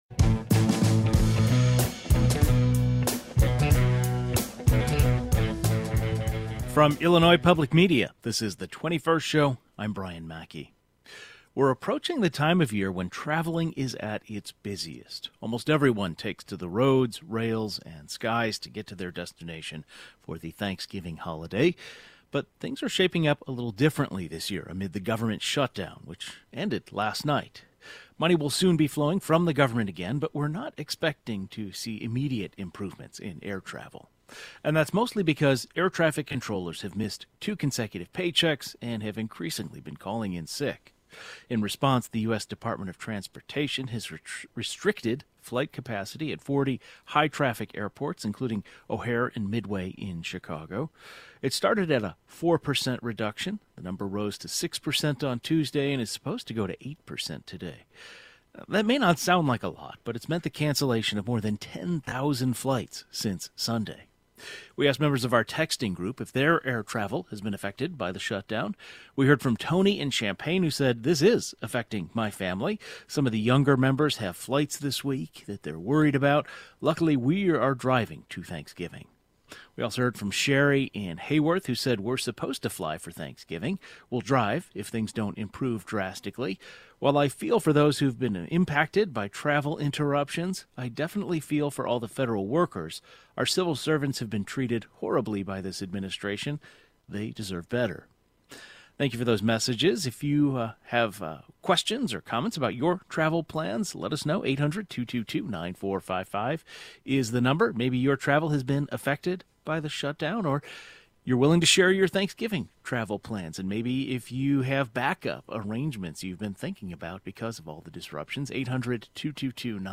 The historic government shut down just ended, but improvements in air travel are not expected right away. Air traffic controllers are increasingly calling in sick resulting in the cancellation of more than 10,000 flights in the last few days. The 21st Show is Illinois' statewide weekday public radio talk show, connecting Illinois and bringing you the news, culture, and stories that matter to the 21st state.